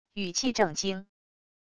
语气正经wav音频